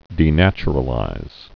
(dē-năchər-ə-līz)